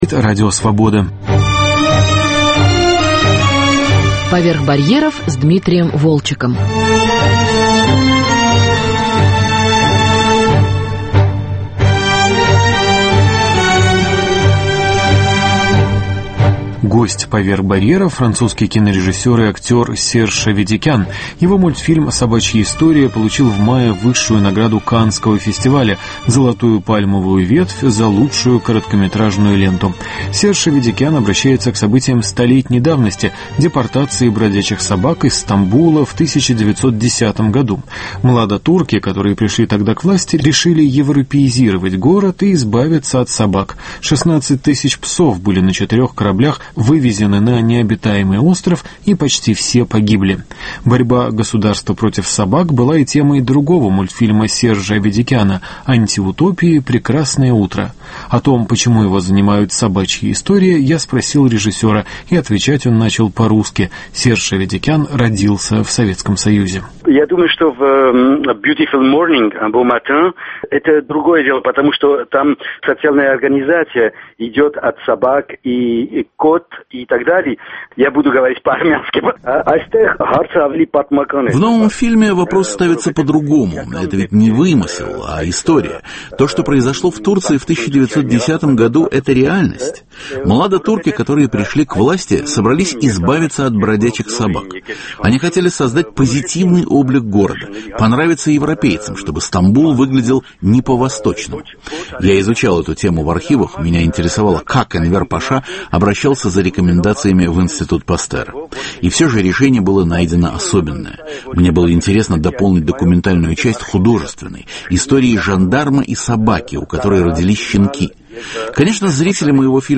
Июльское кинообозрение: Серж Аведикян и Хон Сан Су. Послесловие к пражскому Гурджиевскому конгрессу. Беседа